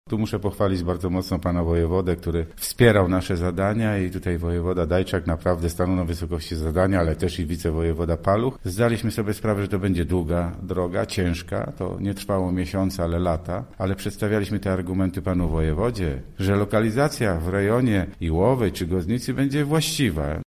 – Na szczęście otrzymaliśmy pomoc od wojewody Władysława Dajczaka i od dziś karetka będzie w Iłowej – mówi Henryk Janowicz, starosta żagański: